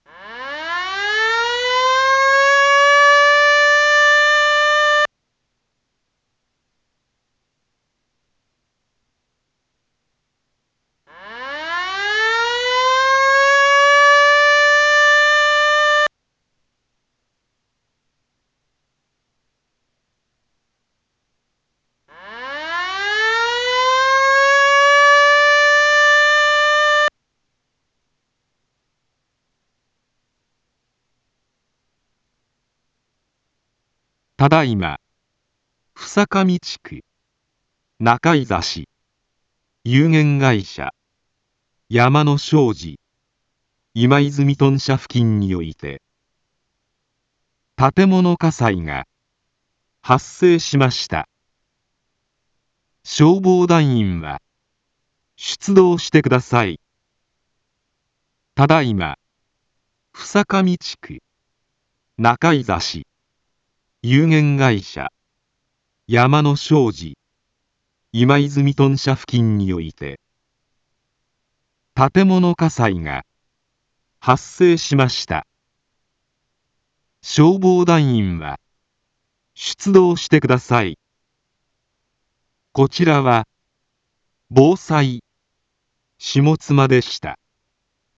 一般放送情報
Back Home 一般放送情報 音声放送 再生 一般放送情報 登録日時：2021-09-18 08:26:32 タイトル：火災報 インフォメーション：ただいま、総上地区中居指有限会社やまの商事今泉豚舎付近において 建物火災が発生しました。